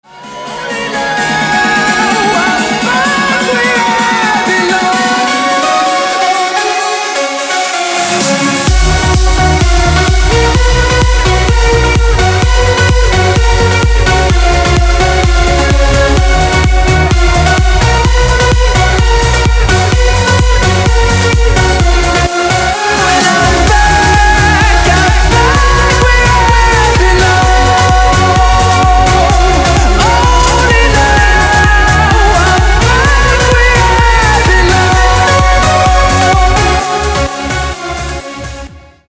• Качество: 192, Stereo
Electronic
club
progressive house
electro